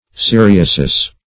Siriasis \Si*ri"a*sis\, n. [L., fr. Gr.